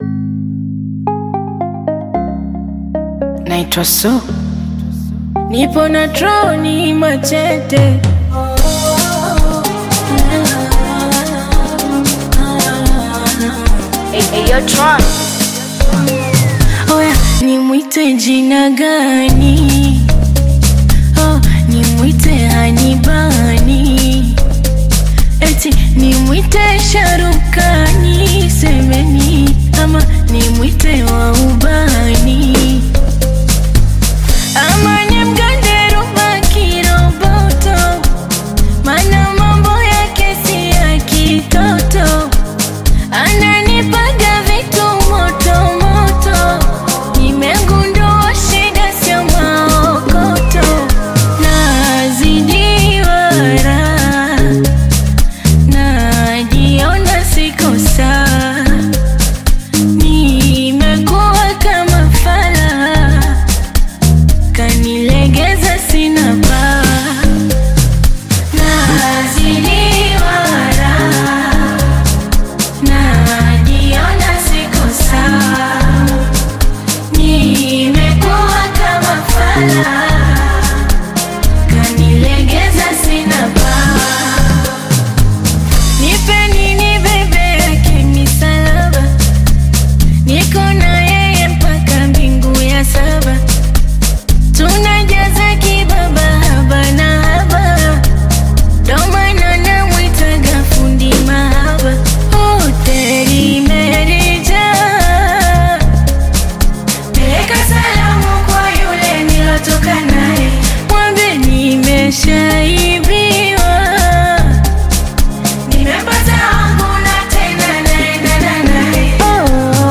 emotional and heartfelt
soft melodies